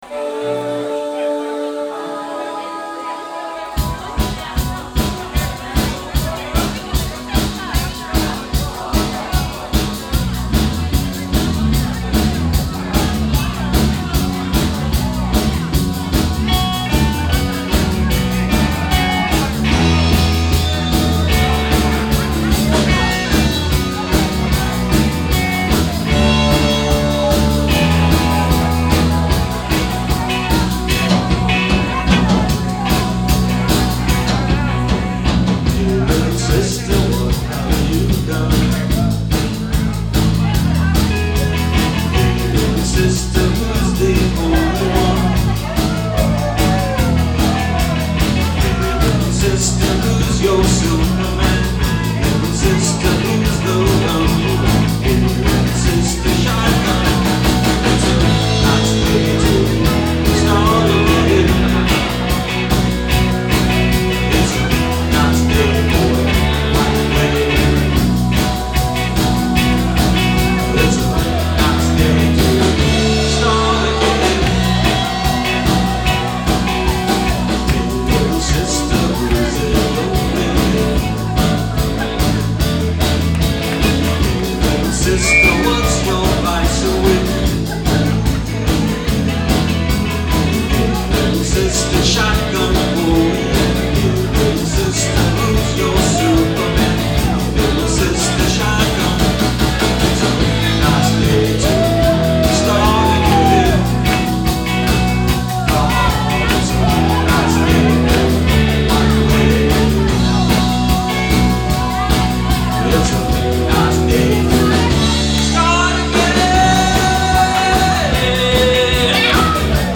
Guitar, Keyboards, Vocals
Bass, Vocals
Guitar, Vocals, Keys, Harmonica
Drums, Vocals